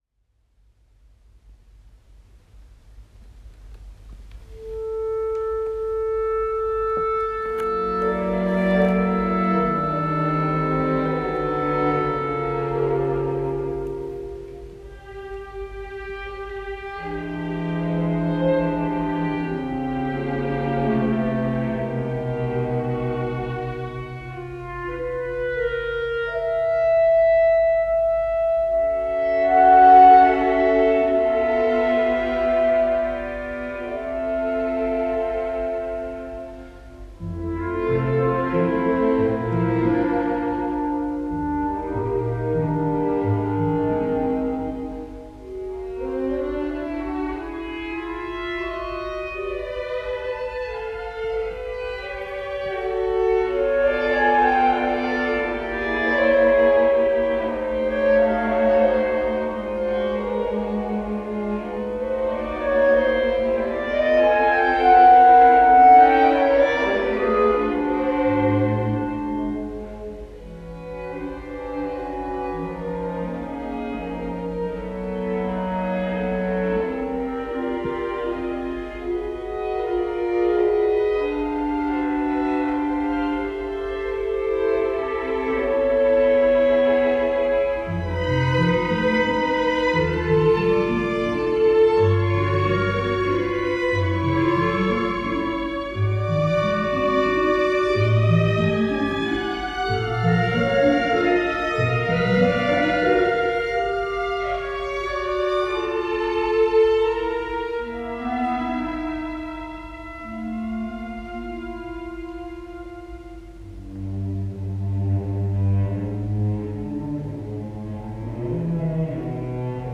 クラリネット四重奏（クラリネット、ヴァイオリン、ヴィオラ、チェロに依る「四季」）／作曲年：1953（昭和28）…
第一楽章（Moderato con grazia）、第二楽章（Allegro appassionato）、第三楽章（Andante religioso）、第四楽章（Allegro）、4楽章全11頁の作品である。